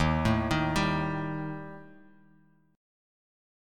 Listen to EbMb5 strummed